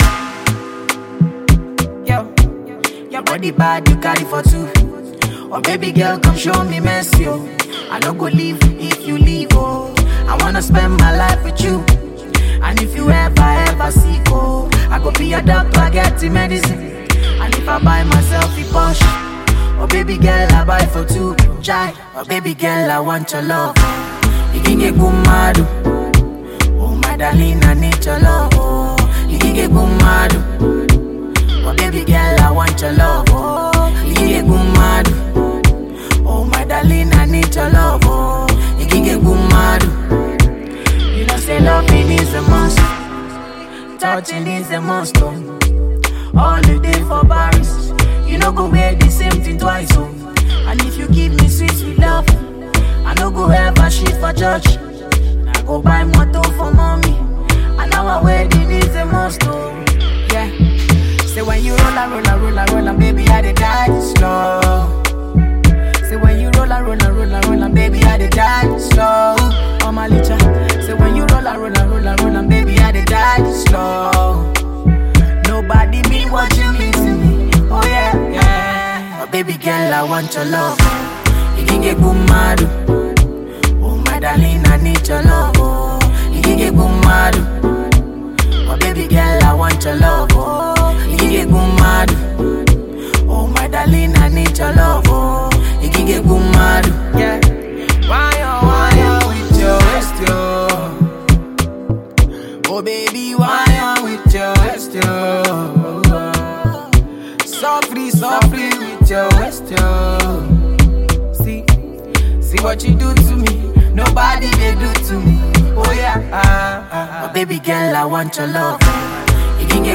smooth tune